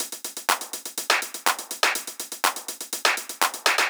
ENE Beat - Perc Mix 4.wav